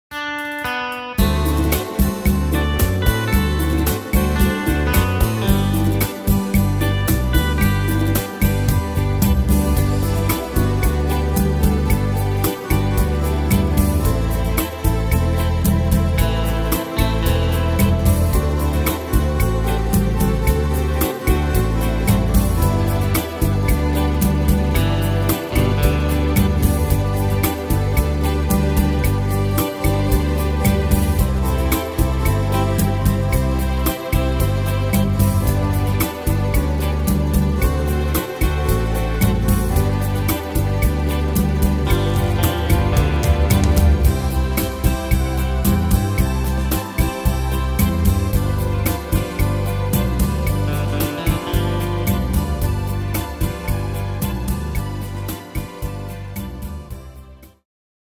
Tempo: 112 / Tonart: G-Dur